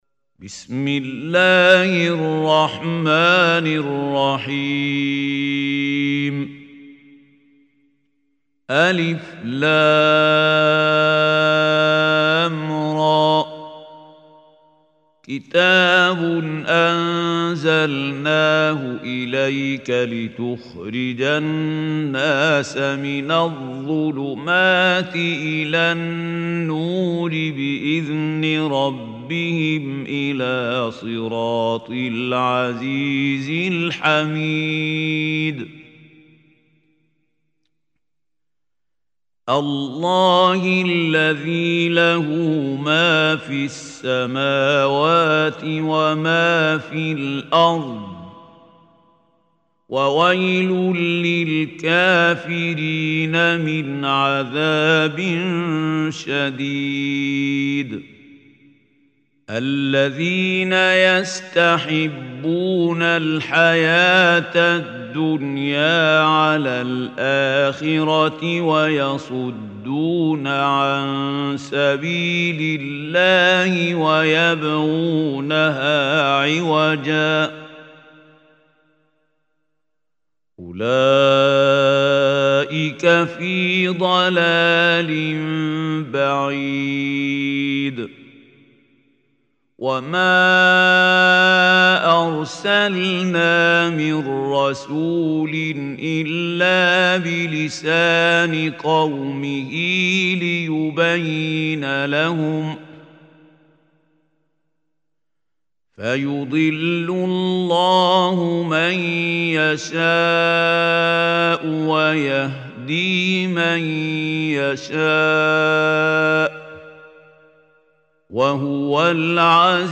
Surah Ibrahim Recitation by Mahmoud Khalil Hussary
Surah Ibrahim is 14 surah of Holy Quran. Listen or play online mp3 tilawat / recitation in Arabic in the beautiful voice of Mahmoud Khalil Al Hussary.